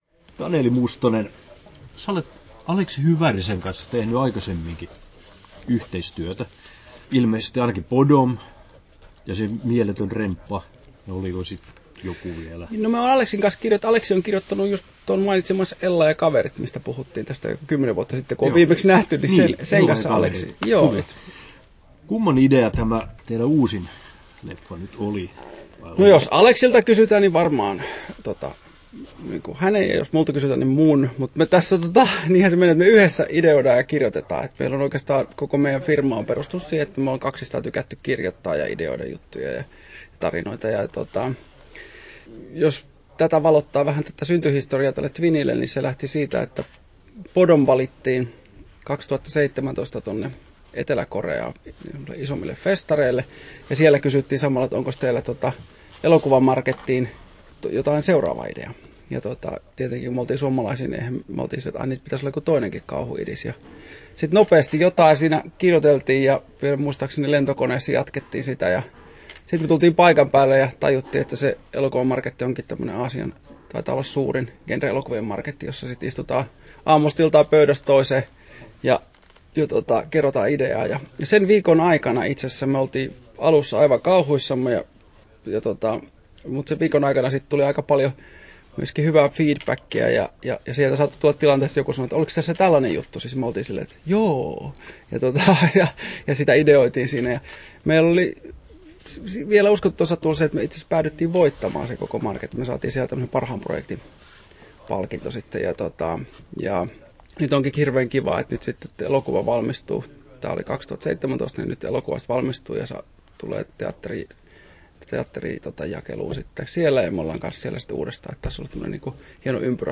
Haastattelussa
8'07" Tallennettu: 29.3.2022, Turku Toimittaja